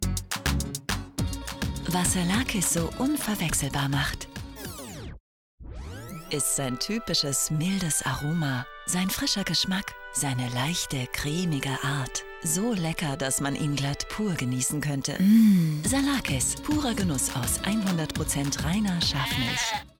sehr variabel, markant
Mittel plus (35-65)
Audiobook (Hörbuch)